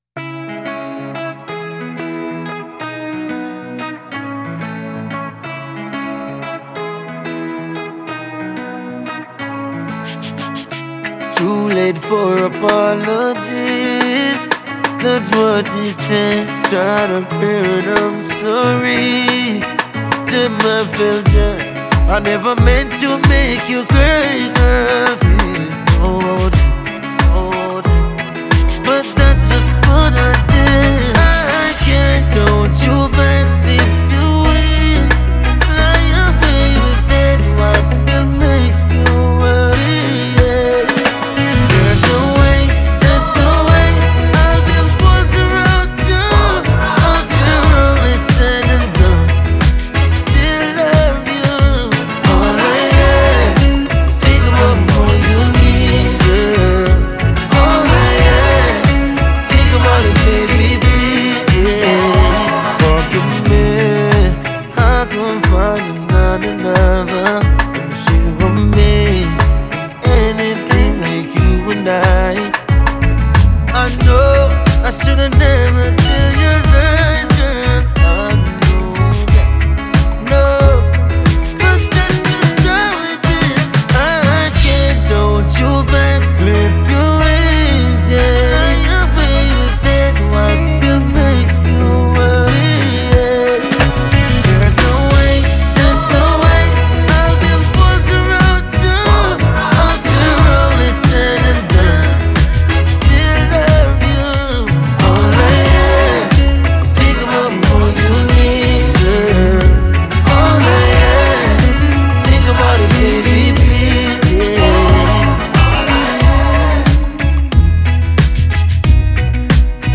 reggae
Enregistré avec des musiciens live en Jamaïque